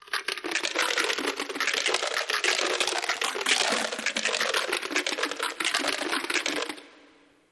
Crunch
Corn Nuts Pouring Into Jar, Interior